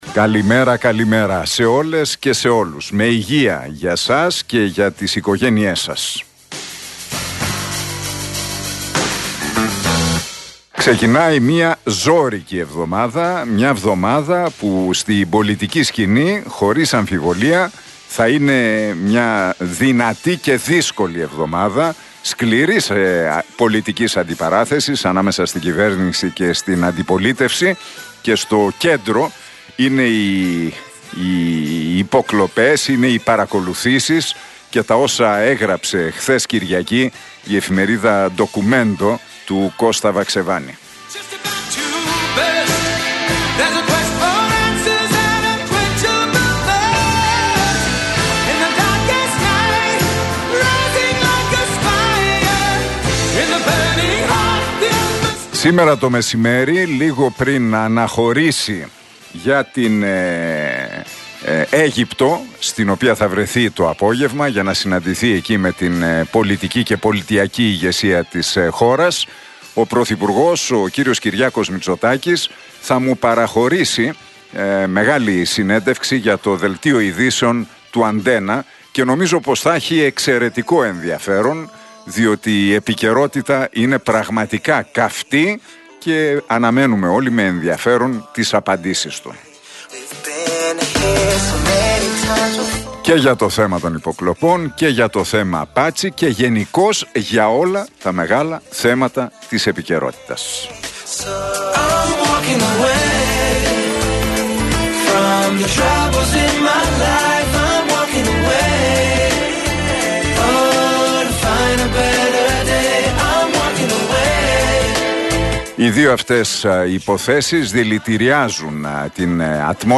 Ακούστε το σημερινό σχόλιο του Νίκου Χατζηνικολάου (7 Νοεμβρίου 2022).